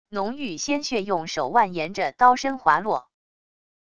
浓郁鲜血用手腕沿着刀身滑落wav音频